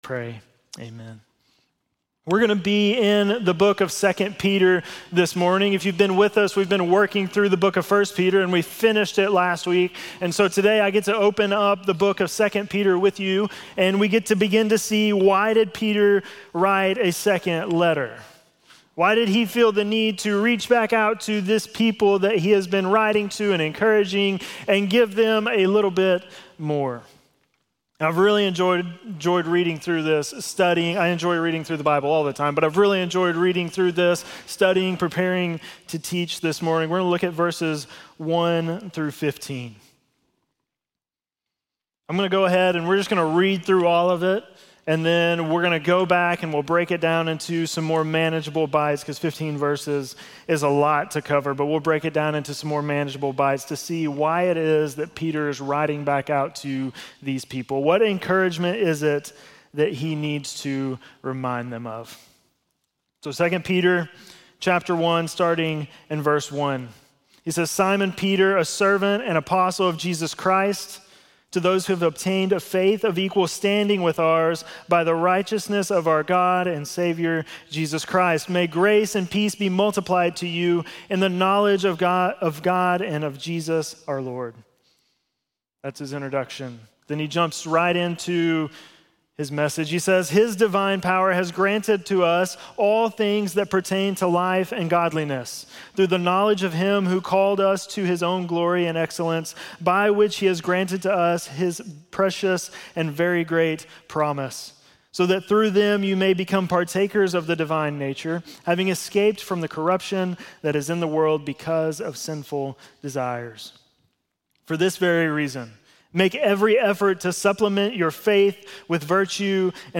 10.19-sermon.mp3